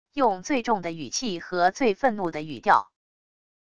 用最重的语气和最愤怒的语调wav音频